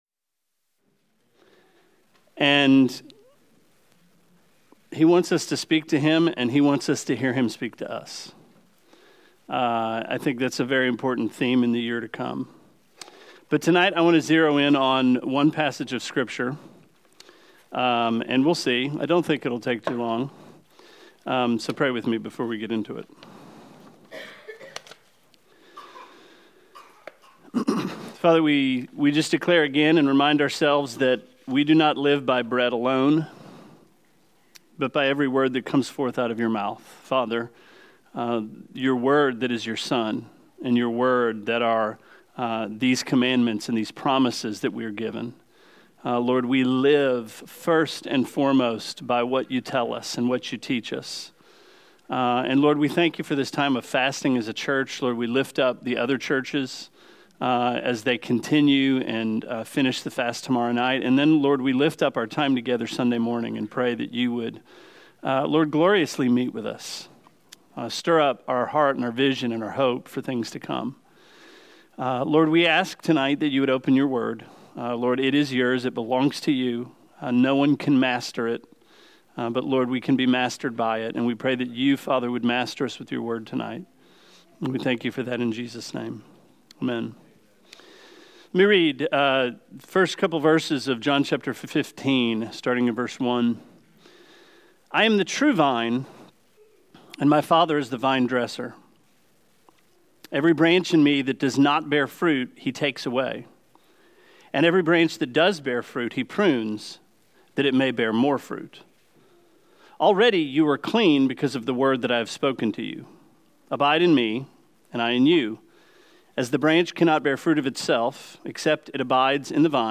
Sermon 12/07: Abide with Him